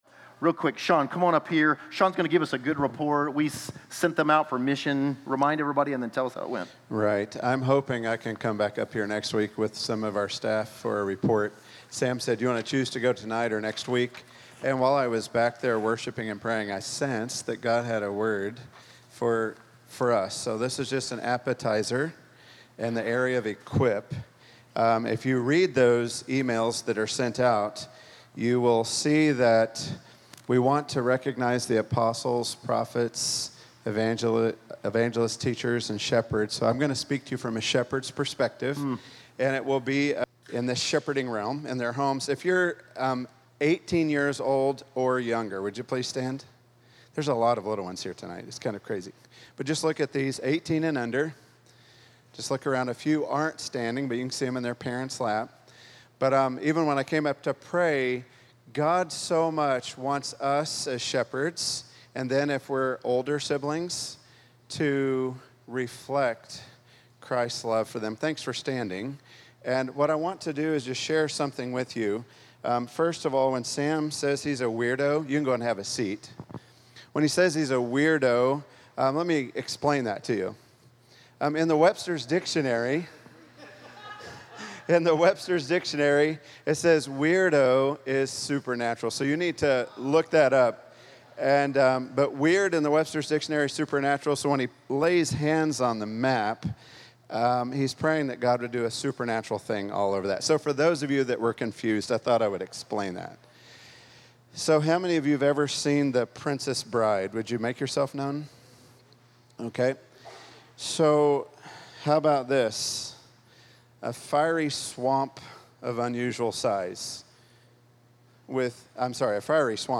Location: Wichita